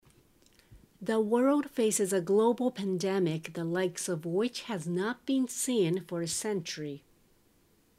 ナチュラルスピード：